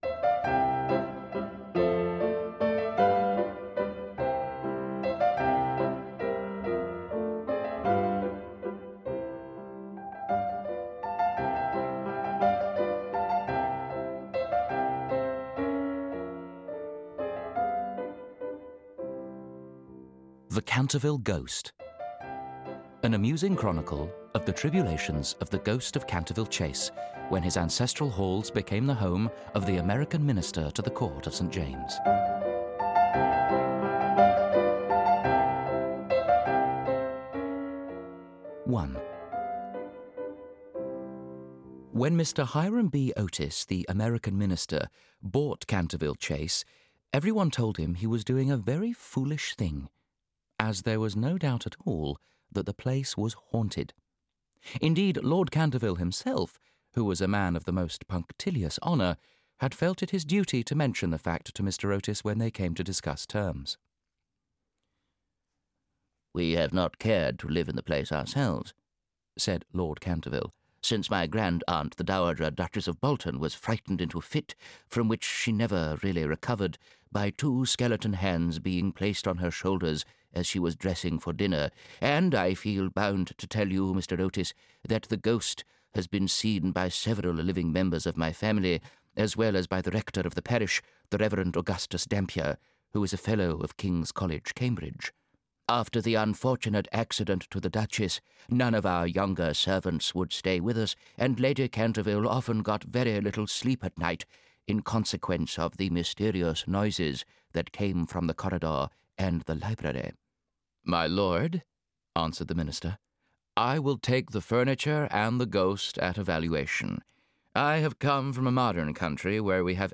The Canterville Ghost (EN) audiokniha
Ukázka z knihy